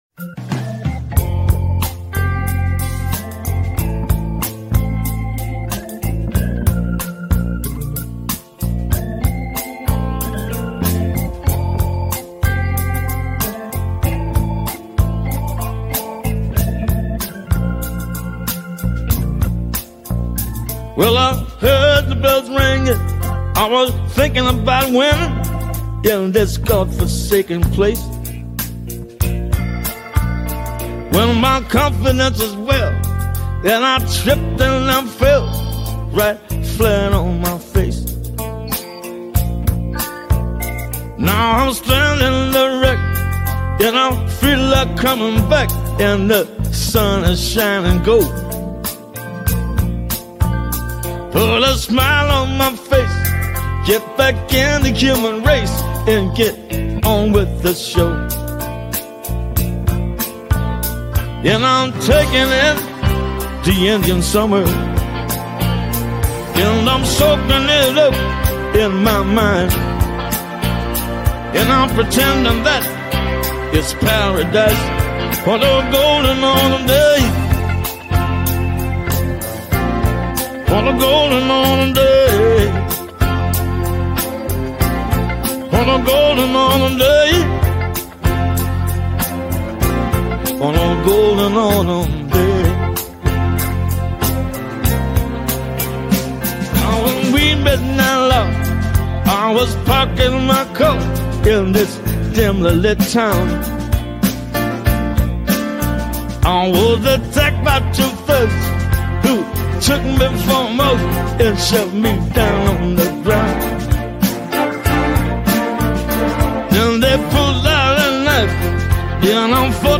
DEBATE TIME GLOBE VS FLAT EARTH‼